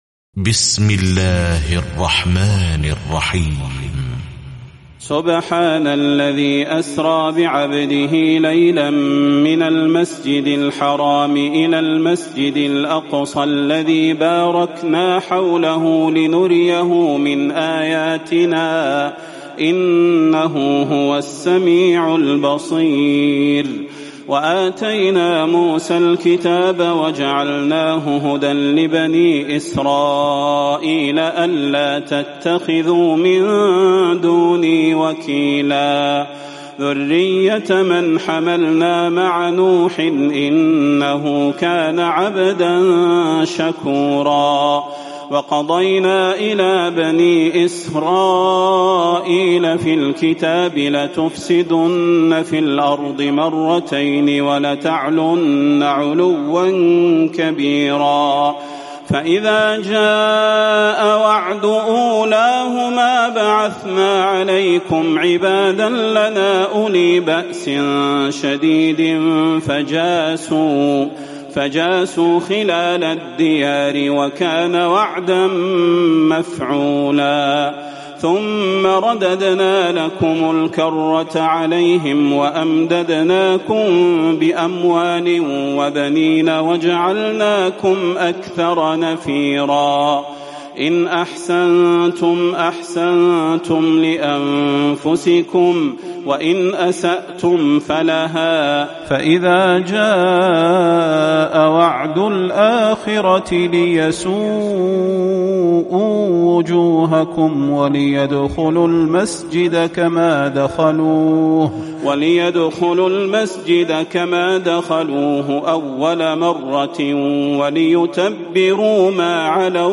تراويح الليلة الرابعة عشر رمضان 1438هـ من سورة الإسراء (1-100) Taraweeh 14 st night Ramadan 1438H from Surah Al-Israa > تراويح الحرم النبوي عام 1438 🕌 > التراويح - تلاوات الحرمين